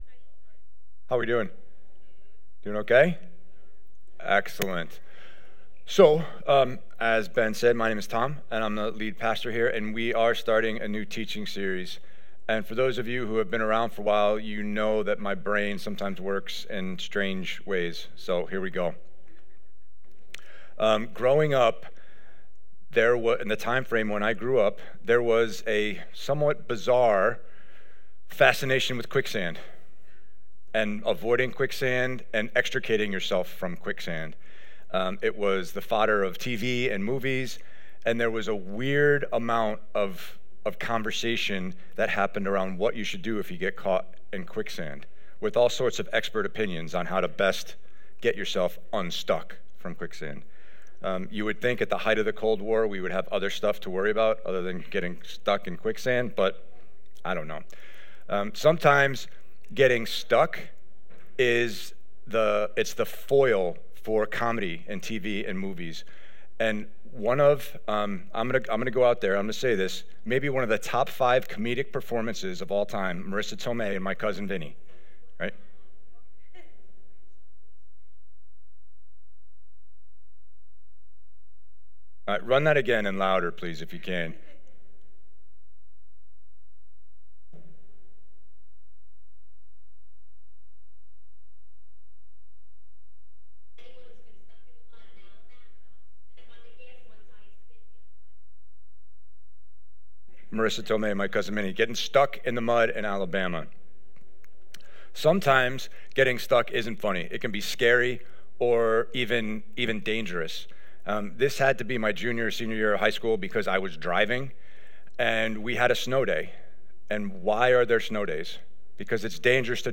Listen in as we begin our new series: Getting Unstuck Timestamps: Intro/Welcome - 4:30 Worship - 7:44 Sermon - 24:00 Worship/Communion - 48:40 Announcements - 59:03 Song List: God So Loved Promises Yes I Will Yet Not I But Through Christ In Me